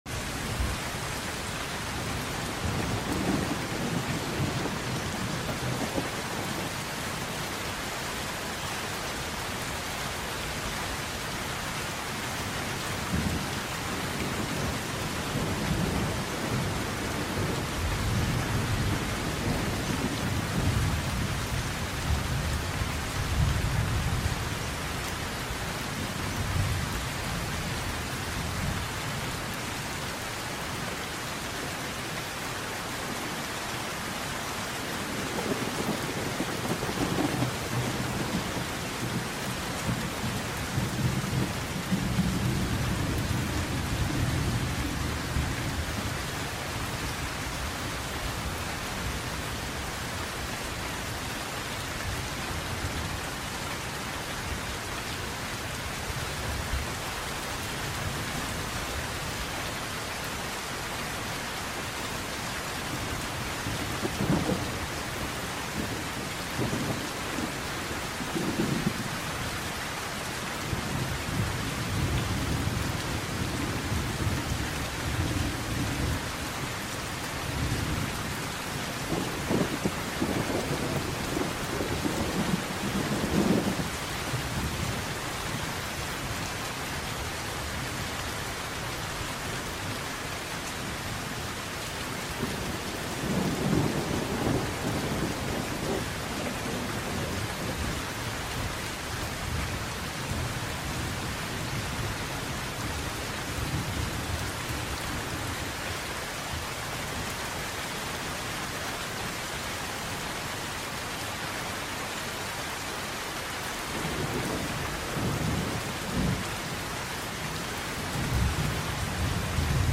Let the soothing sound of rain create the perfect sleep ritual. Each drop washes away stress, while the calm rhythm guides your mind into deep rest. A gentle companion for quiet nights and peaceful dreams.